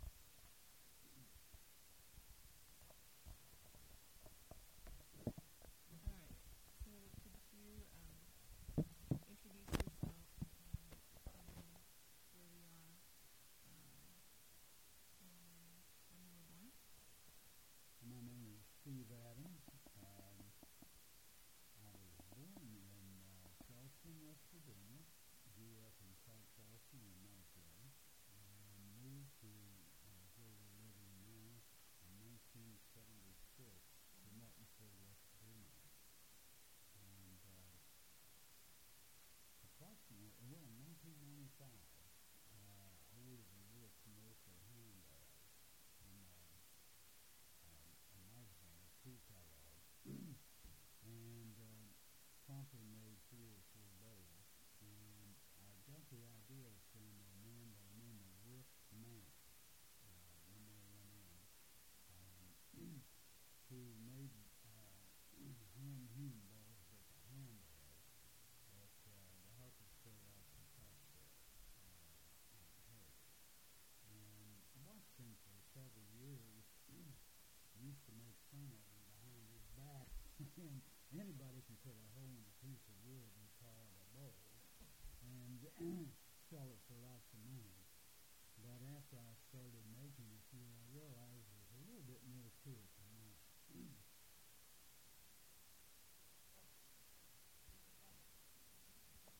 Berkeley County (W. Va.) and Martinsburg (W. Va.)